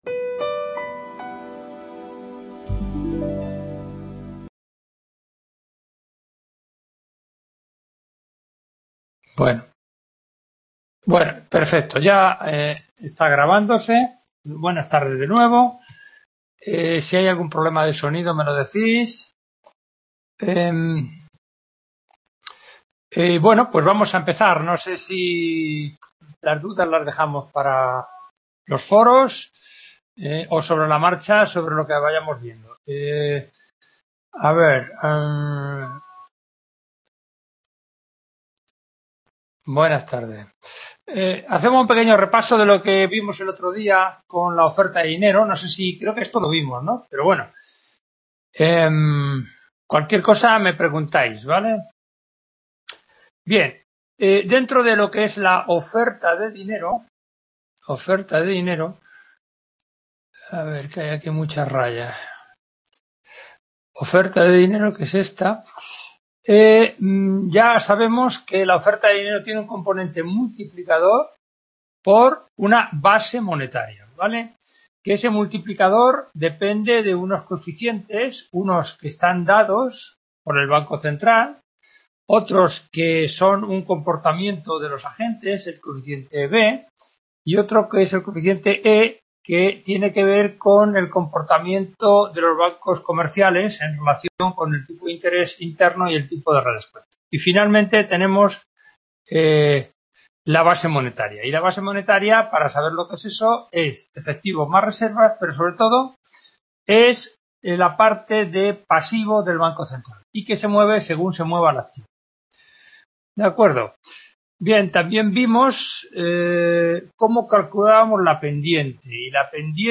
Clase 10ª Renta y Dinero 2019-2020 | Repositorio Digital